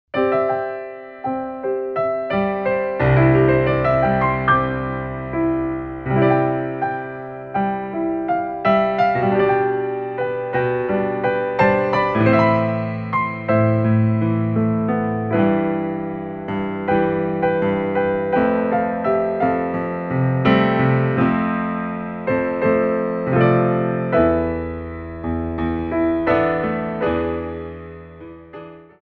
Frappés
4/4 (16x8)